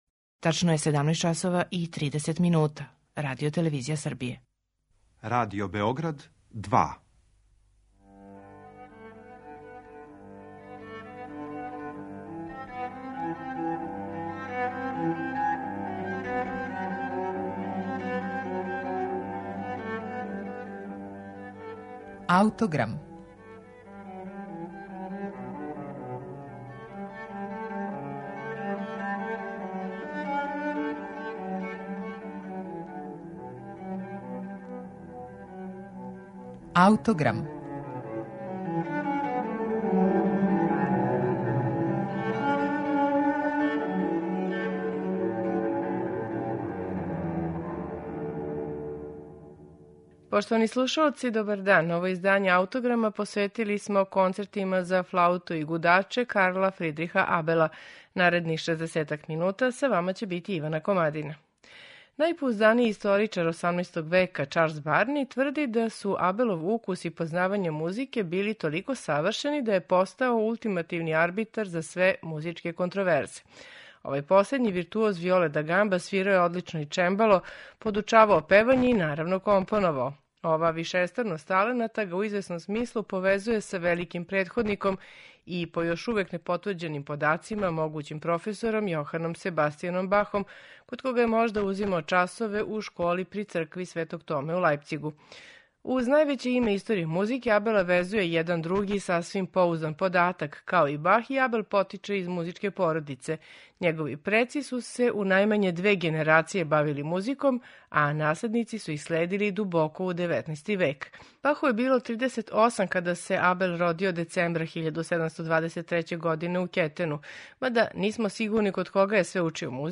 Концерти за флауту и гудаче у Ге-дуру и е-молу Карла Фридриха Абела
флаута и ансамбл Нордик афект.